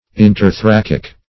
Interthoracic \In`ter*tho*rac"ic\, a.